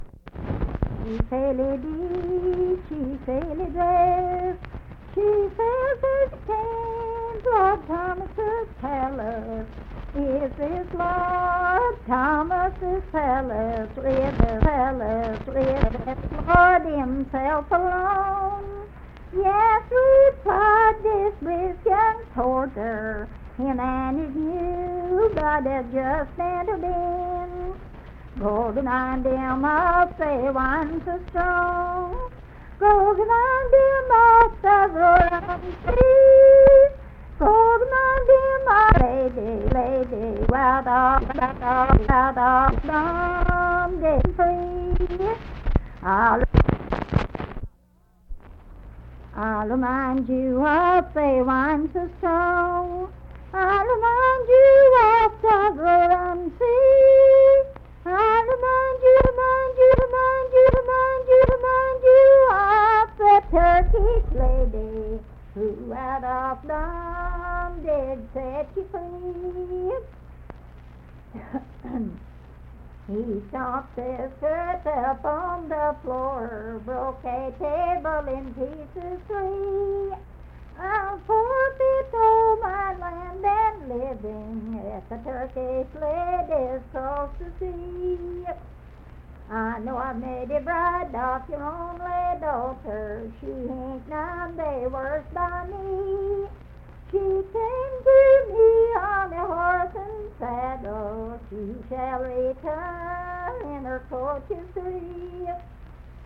Unaccompanied vocal music
Verse-refrain, 5(4).
Voice (sung)